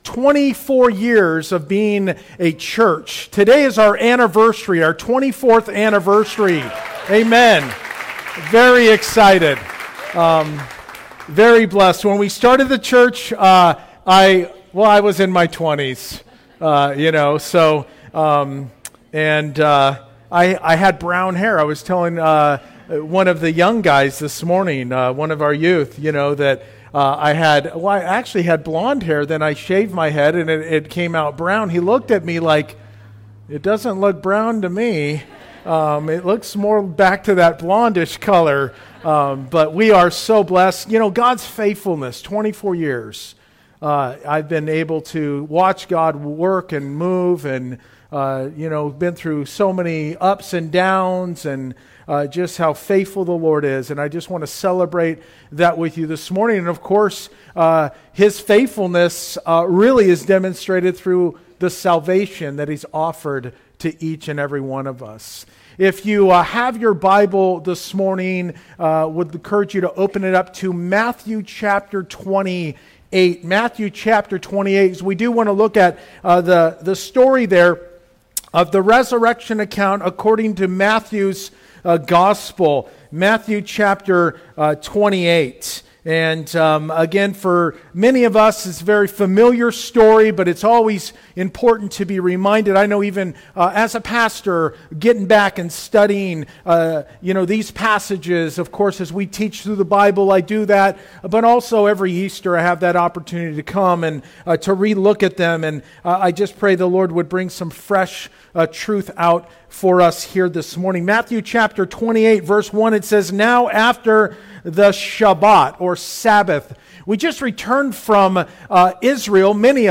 Verse by Verse-In Depth « Psalm 138